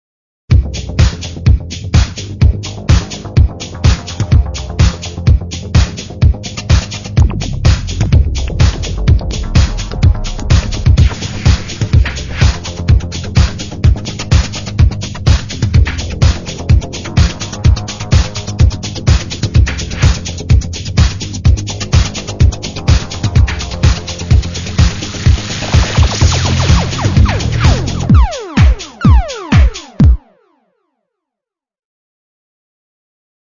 描述：harmonics prodject
标签： cool silly techno
声道立体声